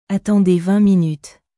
Attendez vingt minutesアトォンデェ ヴァン ミニュットゥ